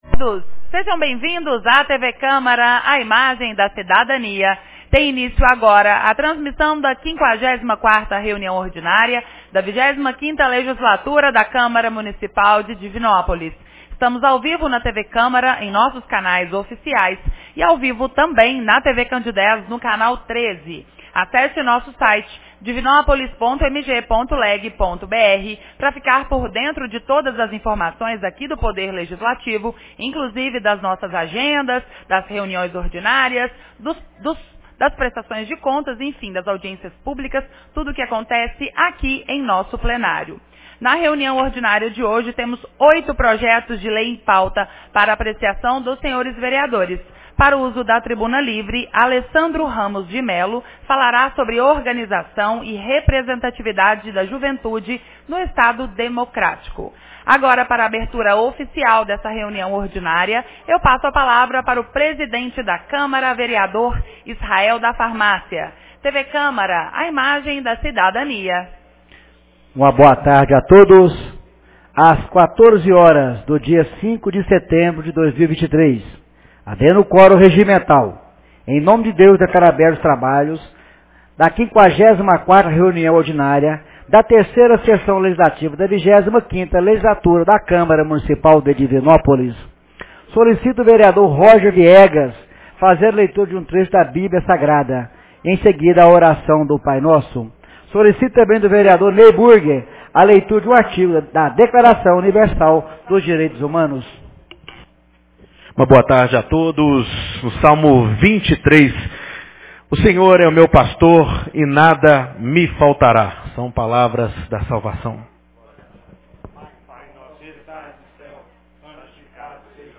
54ª Reunião Ordinária 05 de setembro de 2023